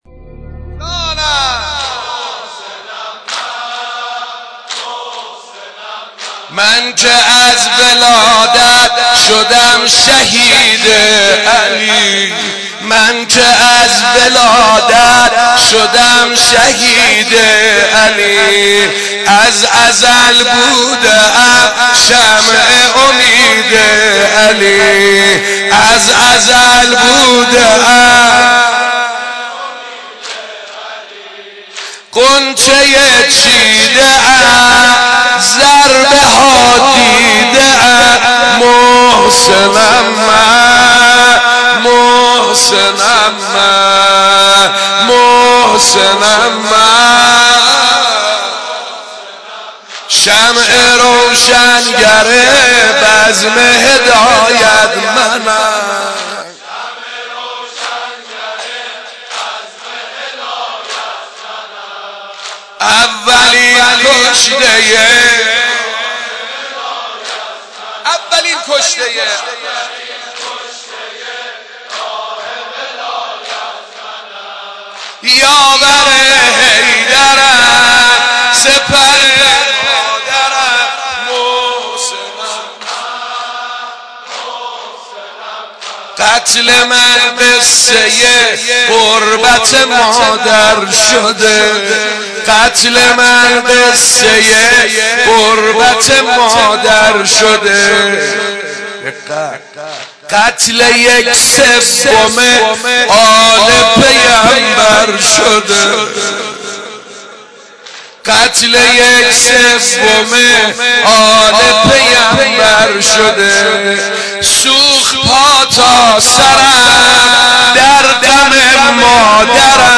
سینه زنی در شهادت بی بی دوعالم حضرت زهرا(س